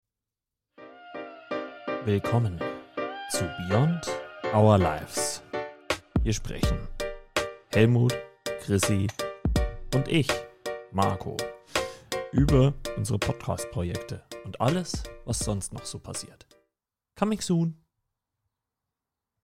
Trailer Beyond our lives
Drei Hosts im Gespräch